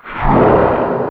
Tsssh1.wav